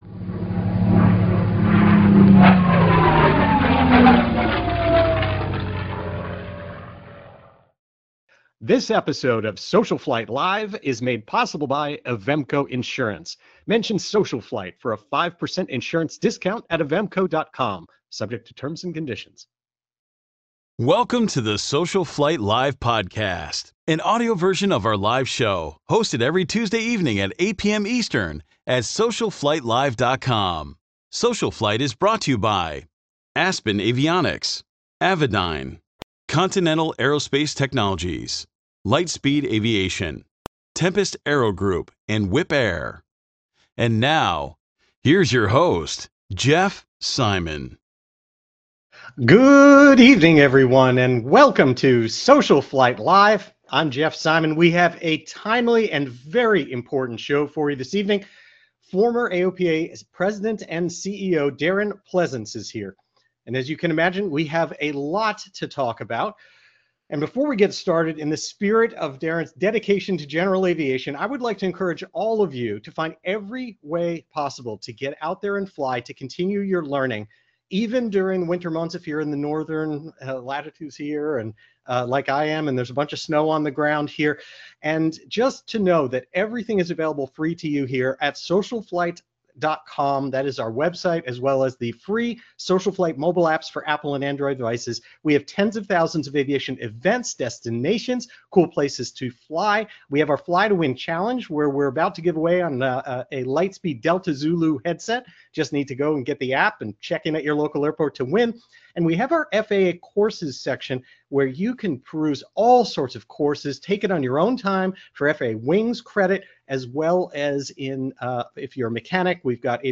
“SocialFlight Live!” is a live broadcast dedicated to supporting General Aviation pilots and enthusiasts during these challenging times.